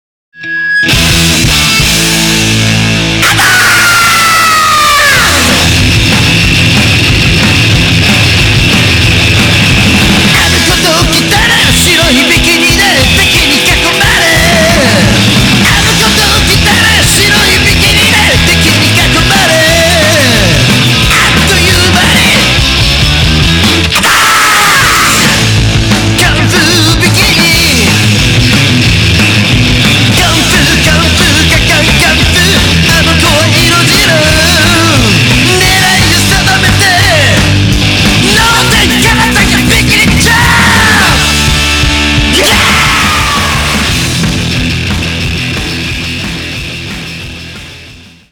ロックンロール